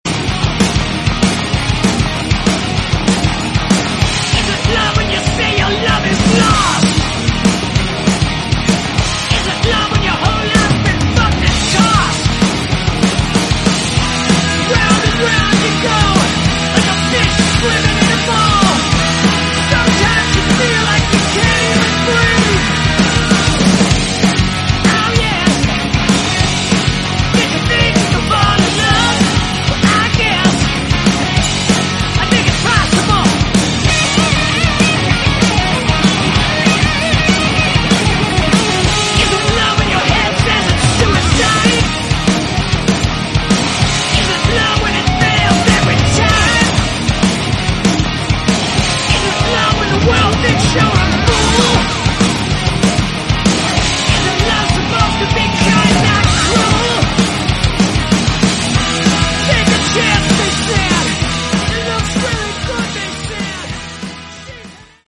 Category: Rock
vocals, guitar, bass
bass guitar